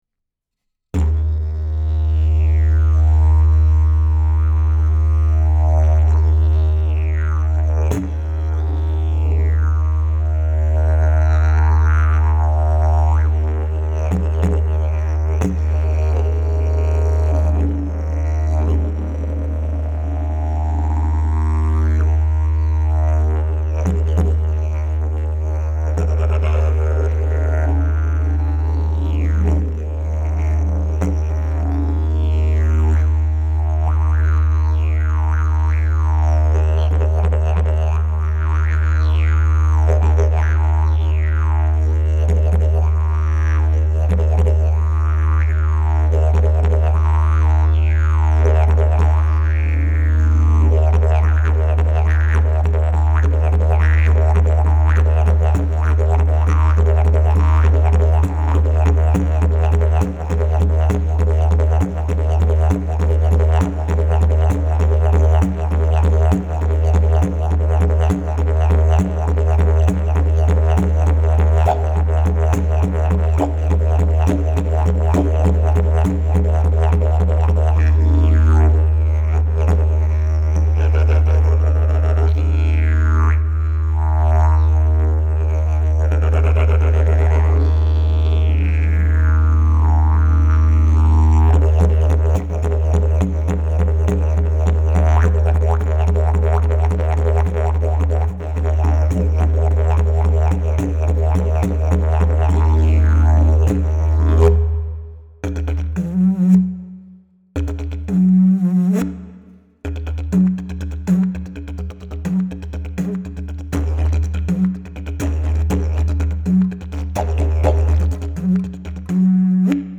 Key: D# Length: 60" Bell: 6.5" Mouthpiece: Canary, Padauk Back pressure: Very strong Weight: 3.8 lbs Skill level: Any
Didgeridoo #637 Key: D#